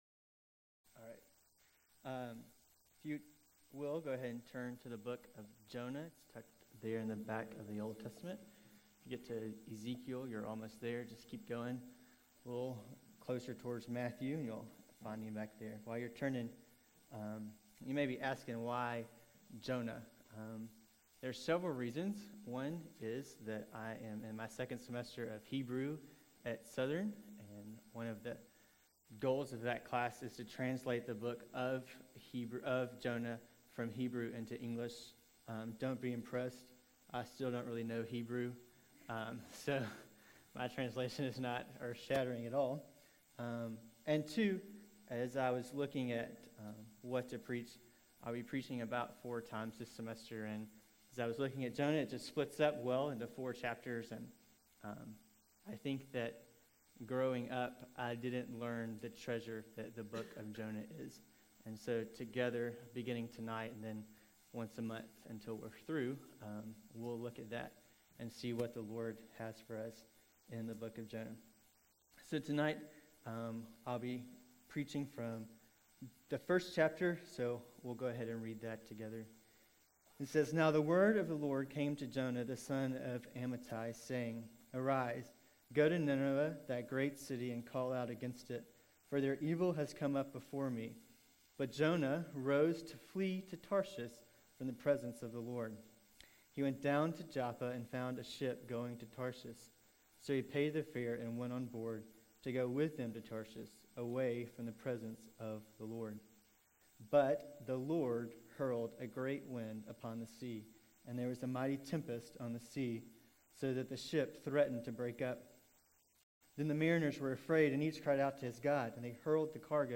February 26, 2012 PM Worship | Vine Street Baptist Church
The full audio of the sermon can be heard by clicking on the date link below, or right-click and select “Save As” to save the file to your computer.